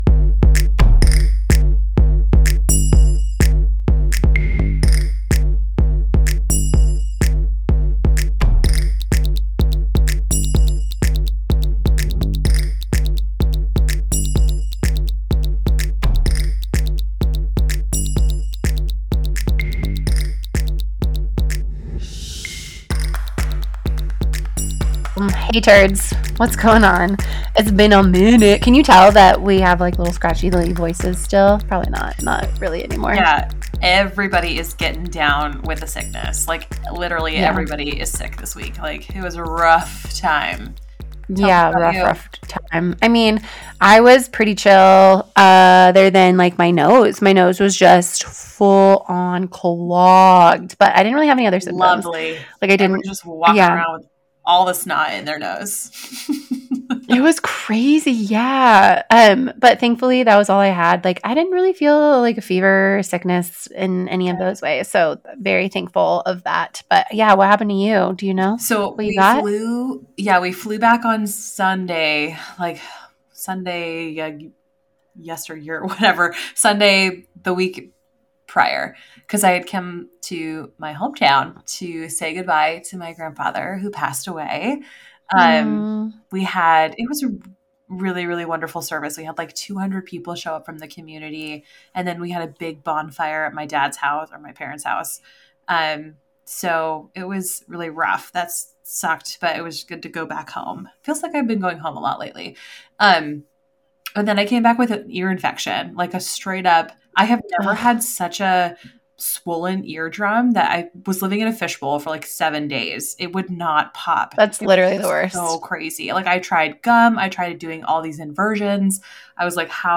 chit chatting and yip yapping